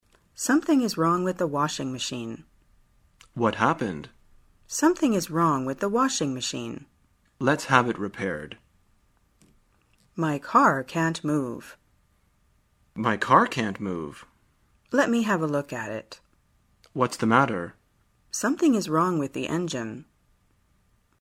在线英语听力室生活口语天天说 第8期:怎样谈论故障的听力文件下载,《生活口语天天说》栏目将日常生活中最常用到的口语句型进行收集和重点讲解。真人发音配字幕帮助英语爱好者们练习听力并进行口语跟读。